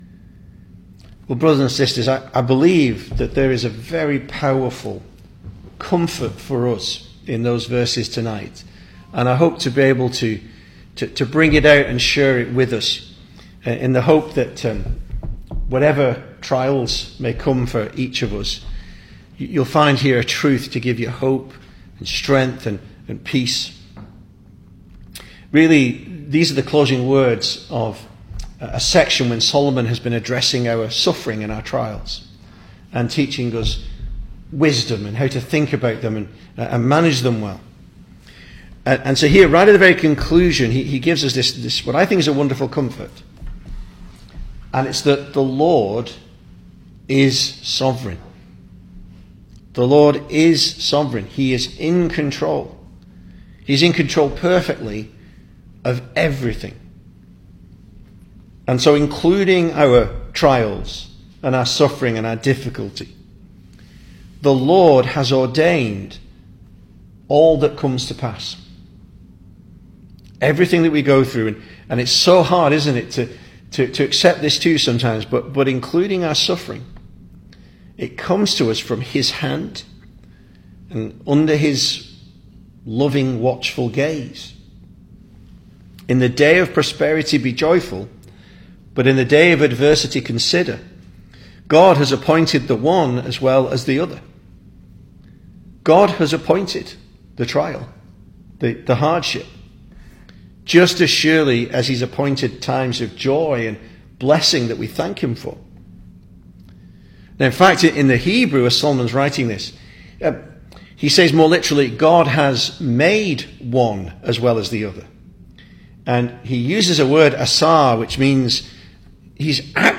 2026 Service Type: Weekday Evening Speaker